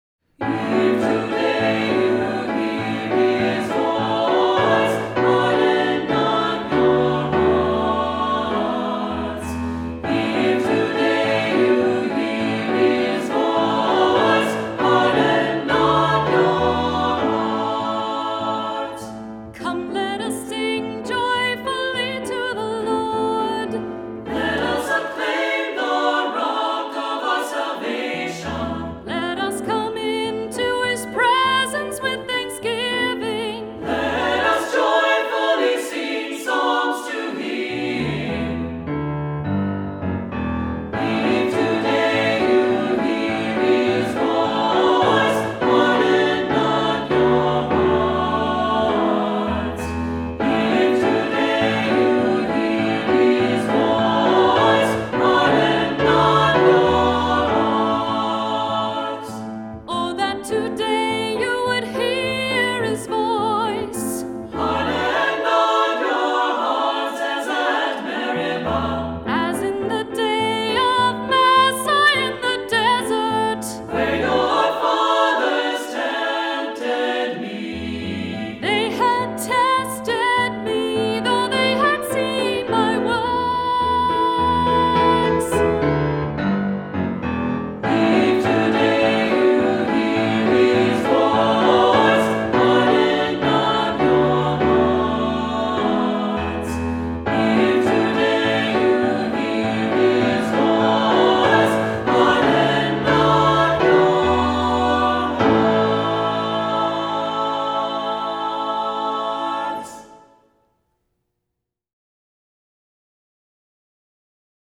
Voicing: 3-part Choir, assembly, cantor